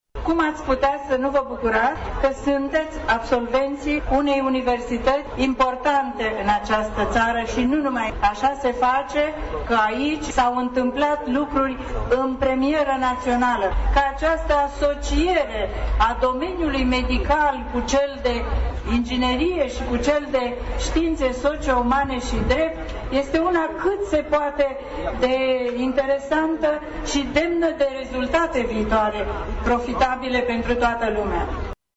Peste 1900 de absolvenți au fost prezenți astăzi pe stadionul Municipal pentru a fi celebrați de părinți, cadrele dactice și de invitații speciali ai evenimentului.
În alocuțiunea sa, ministrul Educației, Ecaterina Andronescu, prezentă la eveniment, a felicitat conducerea univesității pentru ideea de a uni facultăți atât de diverse: